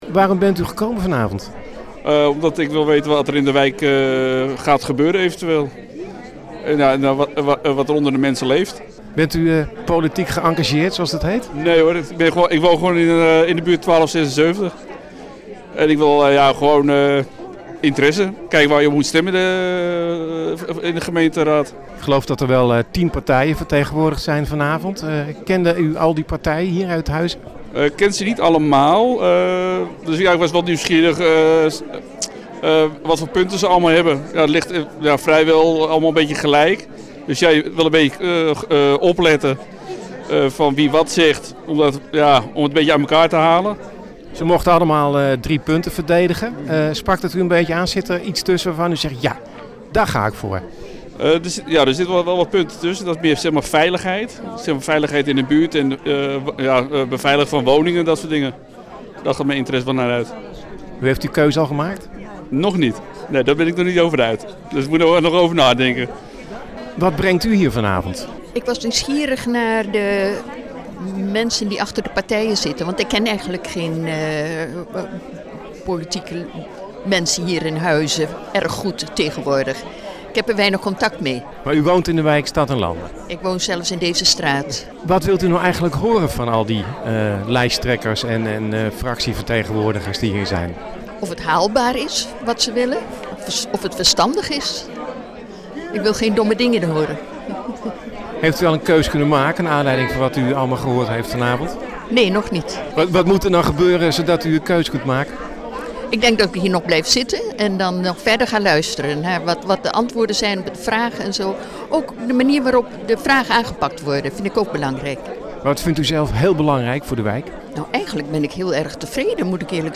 Zo'n debat vond plaats in de wijk Stad en Lande in Huizen.